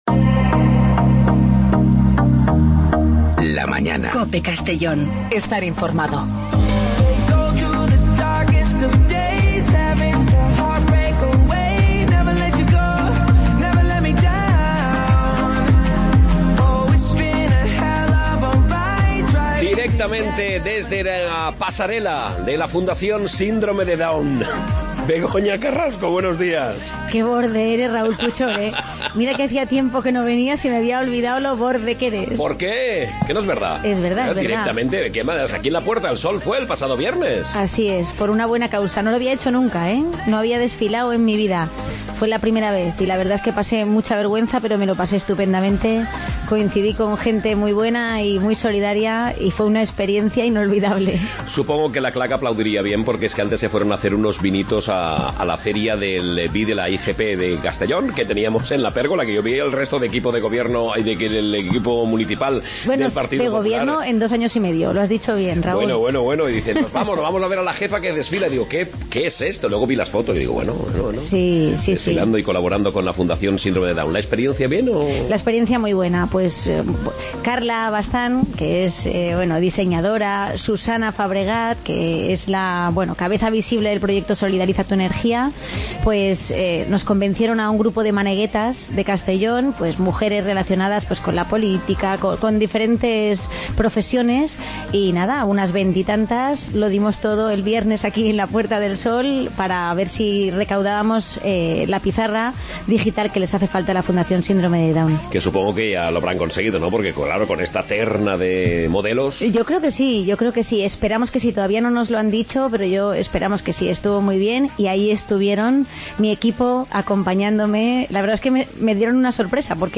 Begoña Carrasco, portavoz grupo municipal popular Castellón.